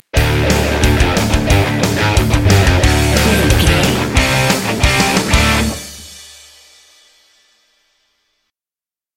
Epic / Action
Aeolian/Minor
C♯
Fast
aggressive
intense
driving
dark
heavy
bass guitar
electric guitar
drum machine